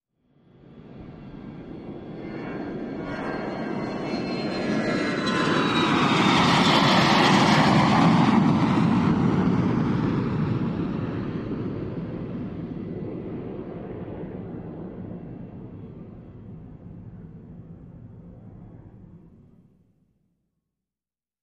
Jet; Large Commercial; Take Off, By, Long Away with Engine Whine